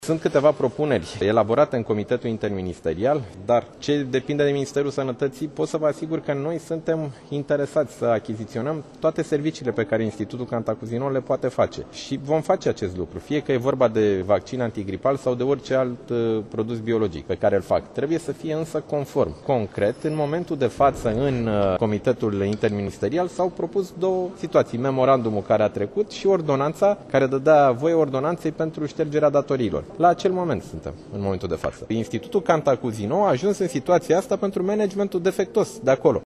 Ministrul Sănătăţii, Nicolae Bănicioiu:
Nicolae-Banicioiu-Cantacuzino.mp3